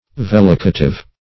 vellicative - definition of vellicative - synonyms, pronunciation, spelling from Free Dictionary
Search Result for " vellicative" : The Collaborative International Dictionary of English v.0.48: Vellicative \Vel"li*ca*tive\, a. Having the power of vellicating, plucking, or twitching; causing vellication.